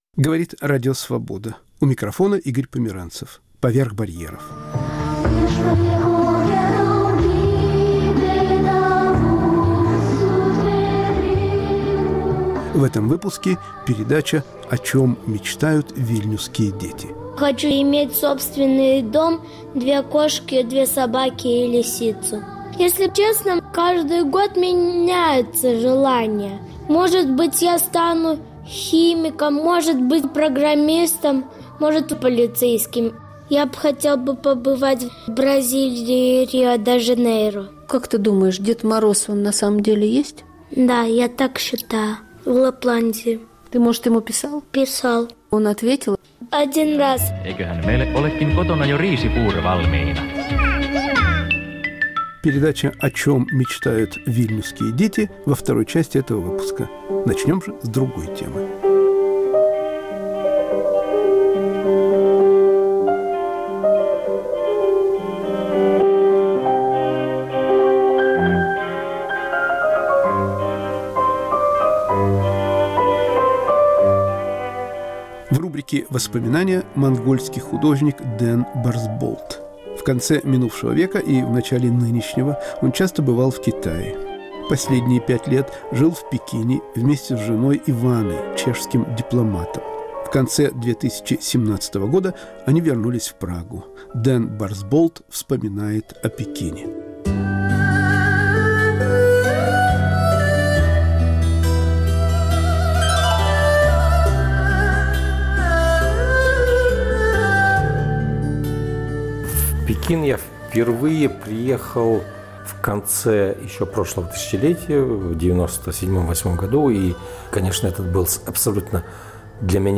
Воспоминания о Пекине.*** О чём мечтают вильнюсские дети? Предновогодние интервью.